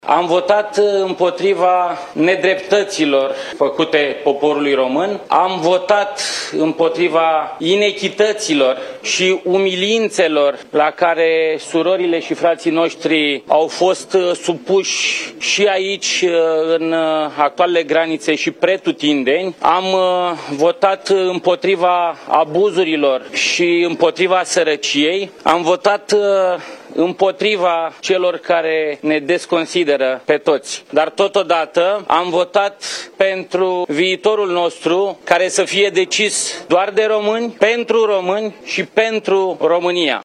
George Simion, declarații la ieșirea de la urne: „Am votat împotriva abuzurilor și împotriva sărăciei” | AUDIO : Europa FM
La ieșirea din secția de votare, George Simion a declarat că a votat „împotriva abuzurilor și împotriva sărăciei”.